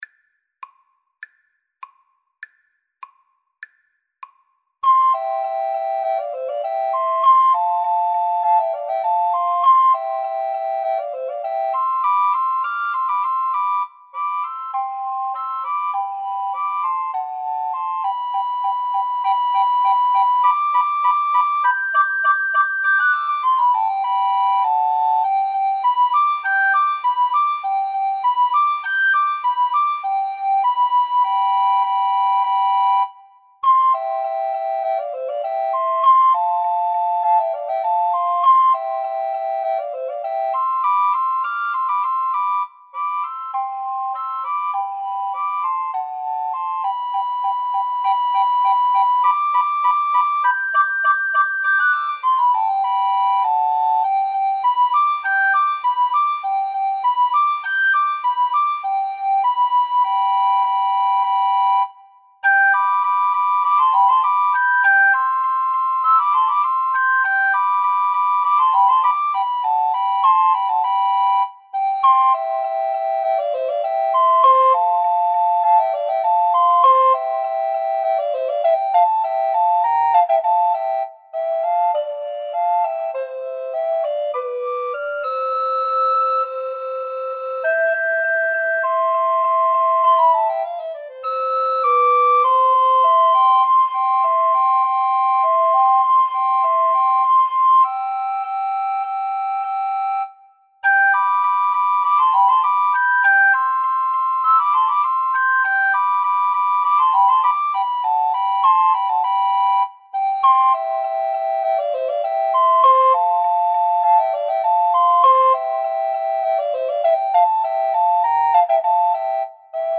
~ = 100 Allegretto
C major (Sounding Pitch) (View more C major Music for Recorder Trio )
Classical (View more Classical Recorder Trio Music)